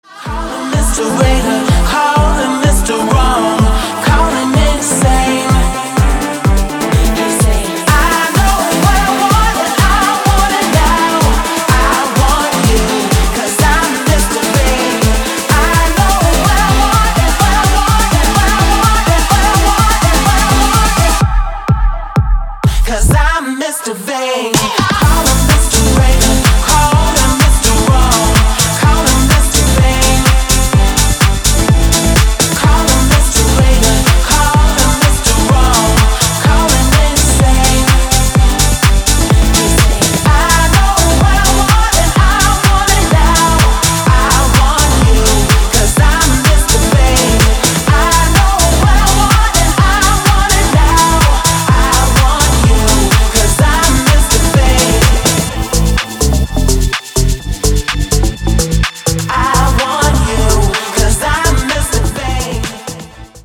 • Качество: 256, Stereo
громкие
женский вокал
dance
Electronic
EDM
house